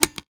machinegun_empty.wav